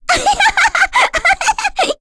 Lilia-Vox_Happy3.wav